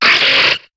Cri de Ténéfix dans Pokémon HOME.